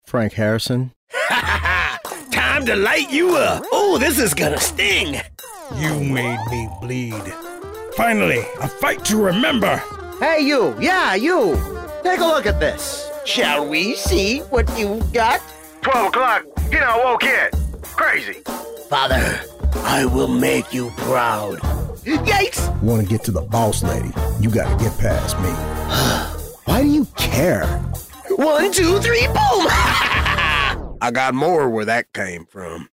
Acoustically treated home studio for pristine audio recordings.